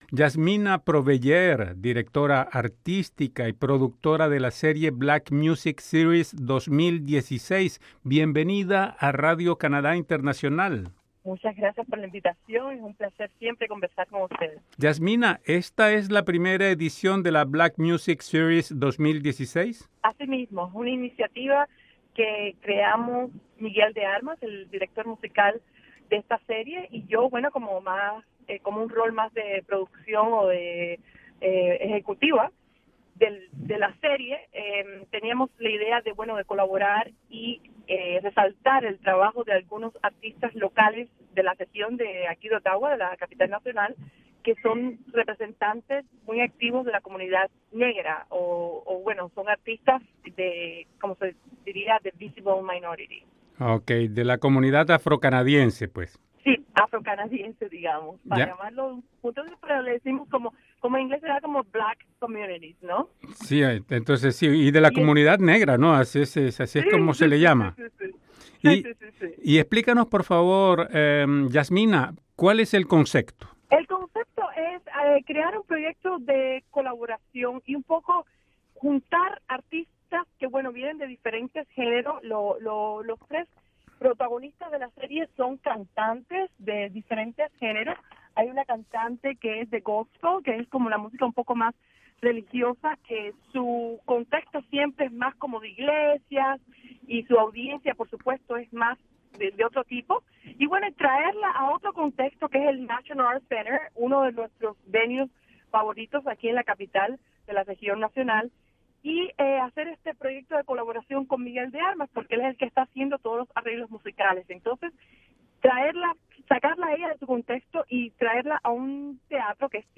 Interview-2.mp3